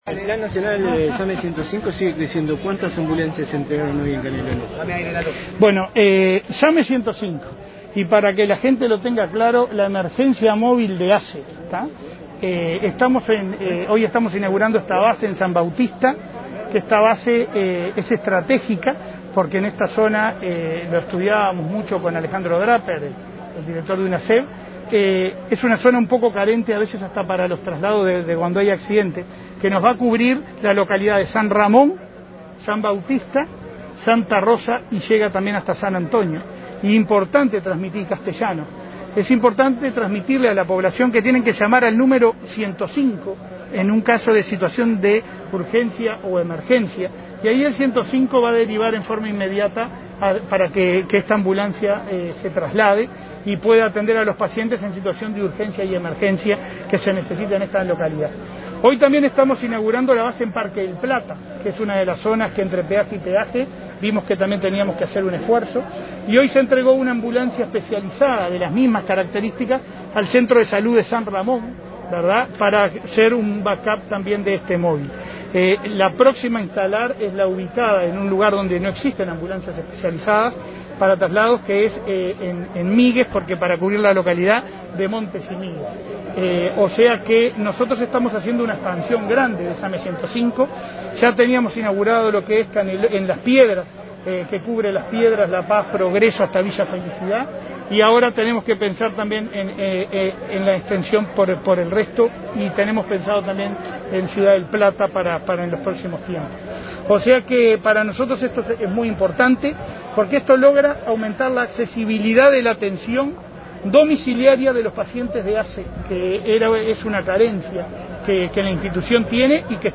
Declaraciones a la prensa del presidente de ASSE, Leonardo Cipriani